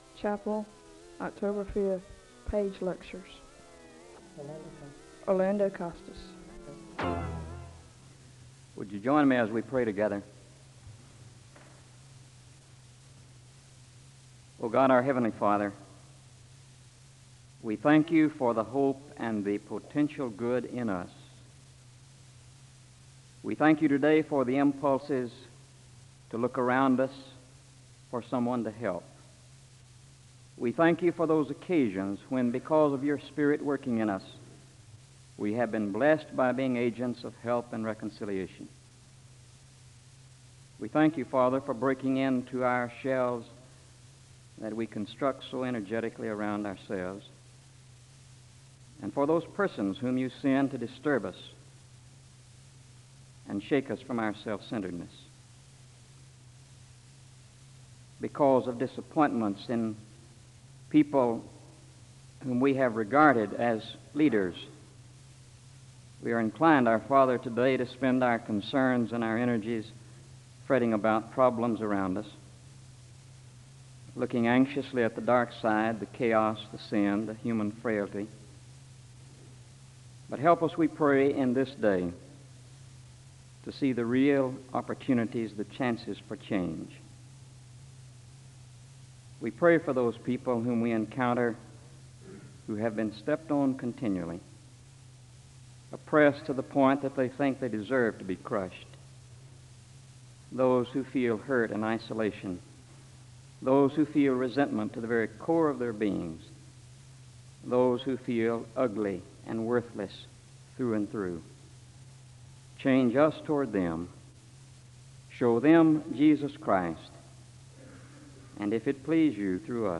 The service begins with a word of prayer (00:00-02:19).
The speaker ends the service with a benediction (44:55-45:57).